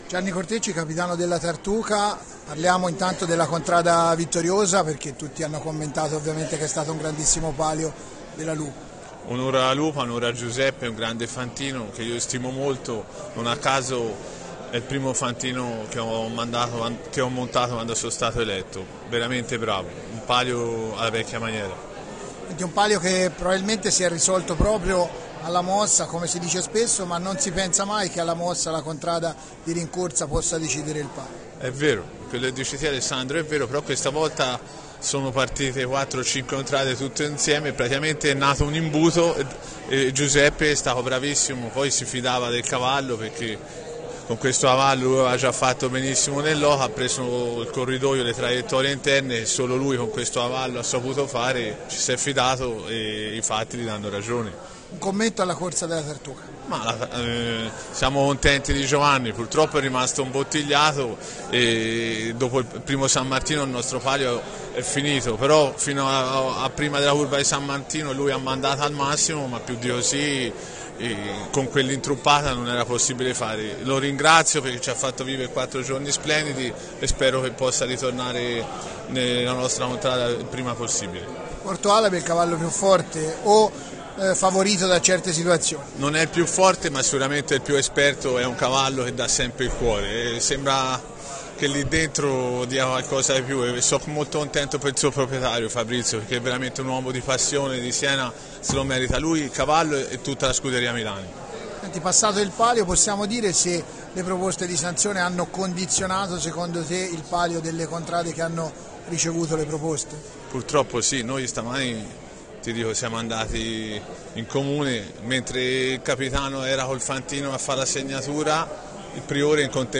Interviste
Come di consueto, dopo la carriera, abbiamo raccolto i commenti dei capitani delle contrade che hanno partecipato al palio del 16 agosto.